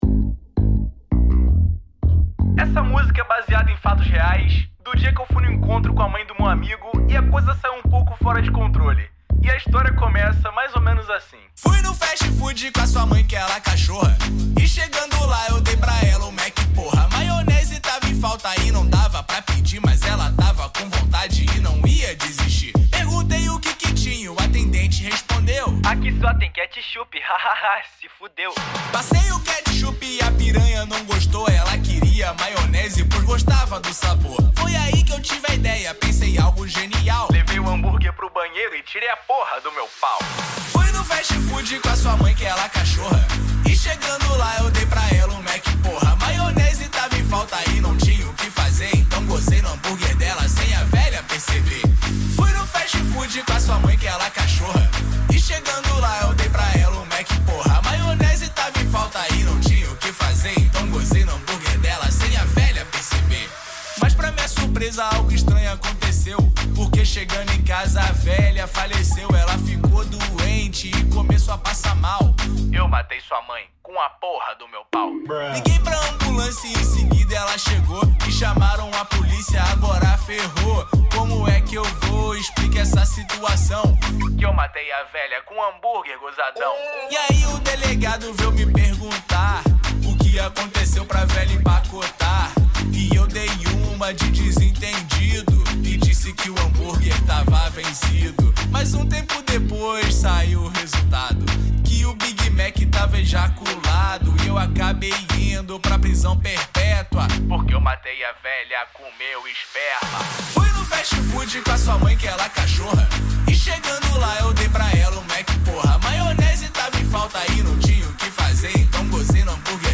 2024-05-11 21:17:27 Gênero: Trap Views